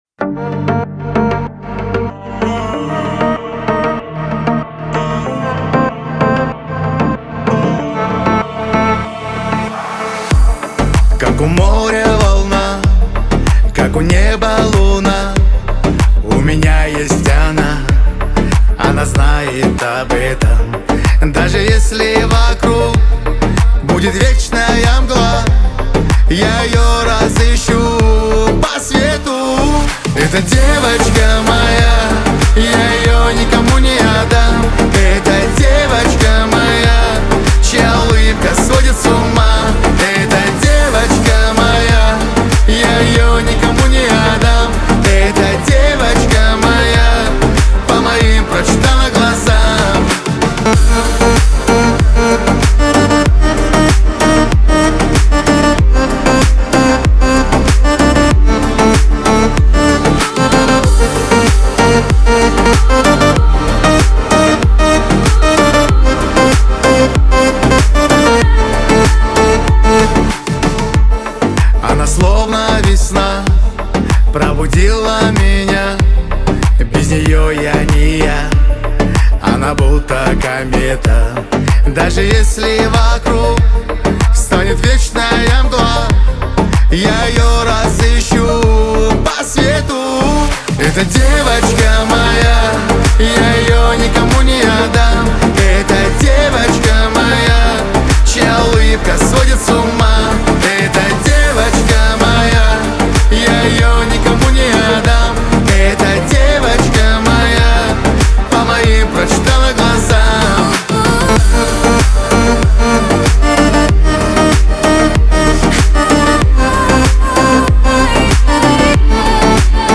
Качество: 320 kbps, stereo
Кавказская музыка